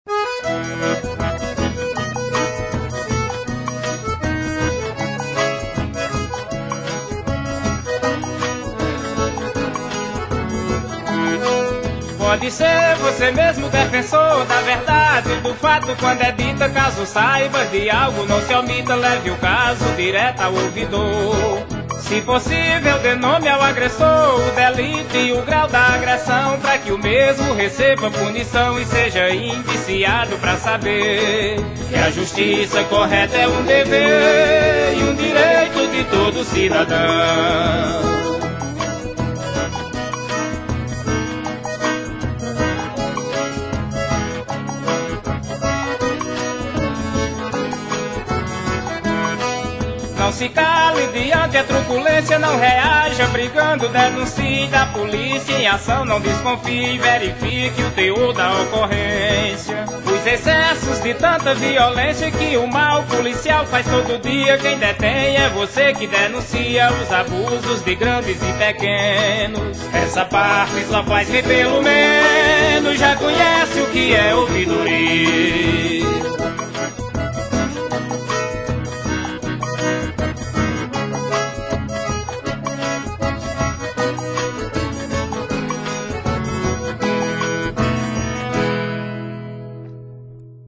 Faixa 4 - Spot Forró 2 Faixa 11 - Spot Raiz 3